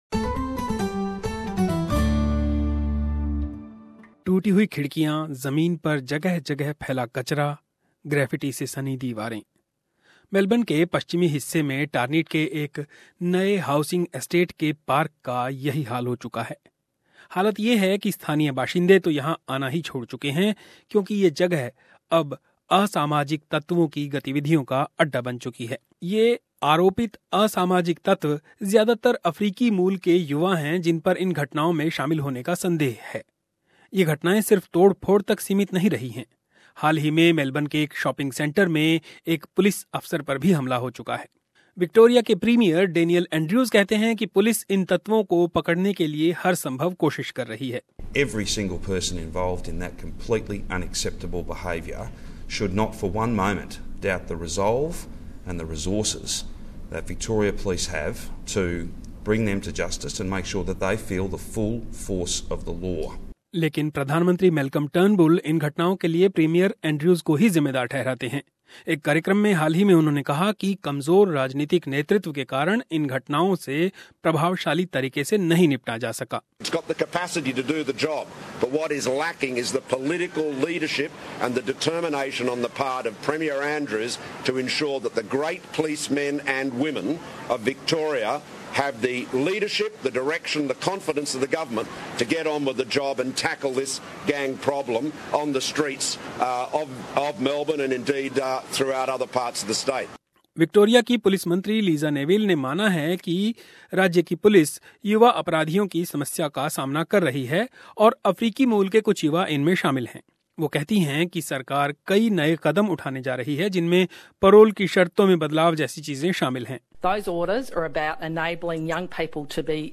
एक रिपोर्ट...